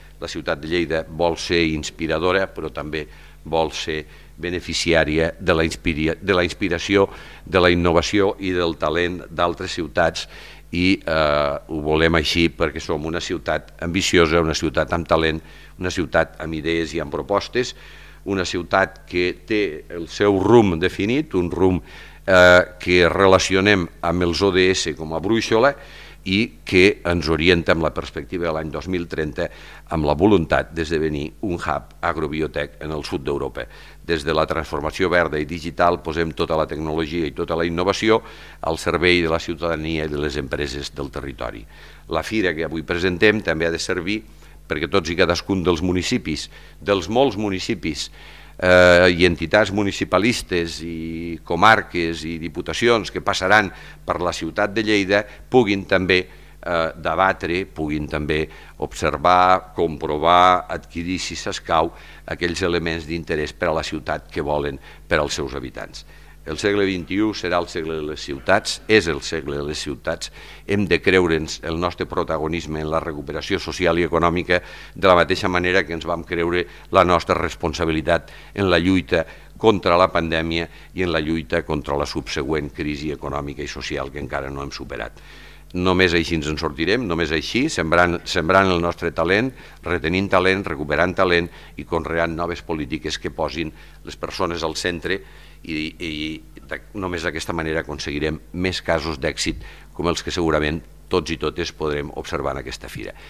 tall-de-veu-de-lalcalde-miquel-pueyo-sobre-la-inauguracio-de-municipalia